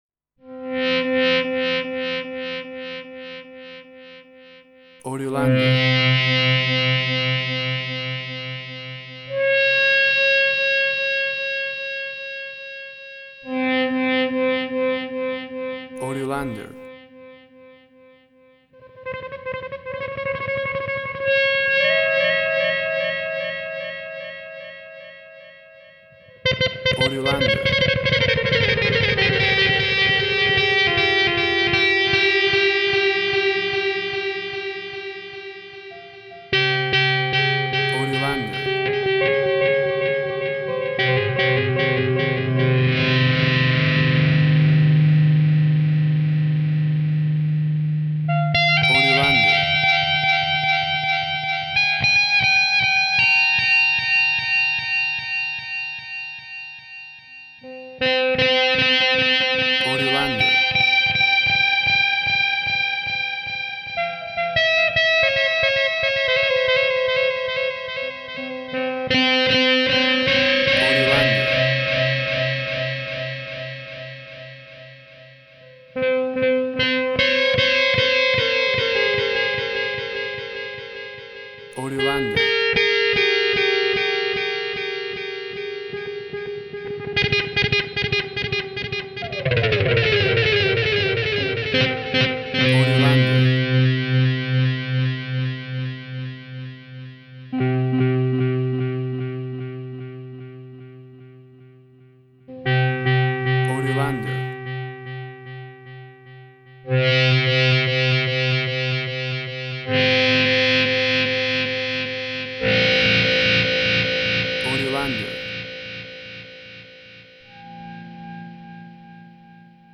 WAV Sample Rate: 24-Bit stereo, 44.1 kHz